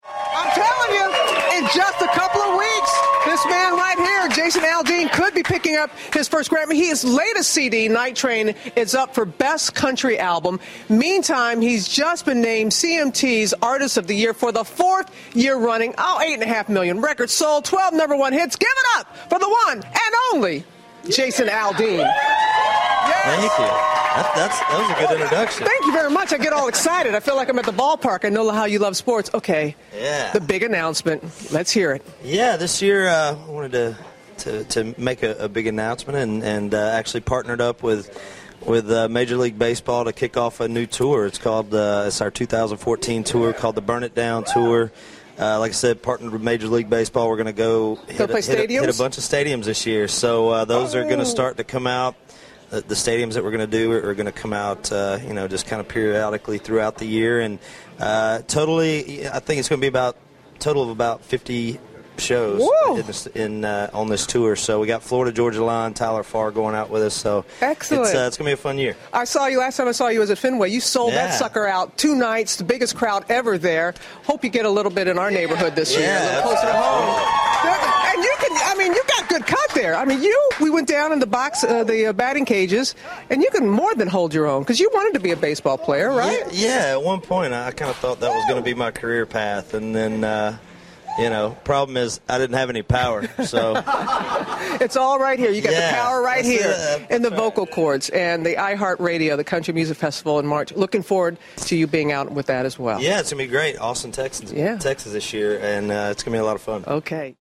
访谈录 2014-01-19&01-21 “乡村歌王”杰森·阿尔丁 听力文件下载—在线英语听力室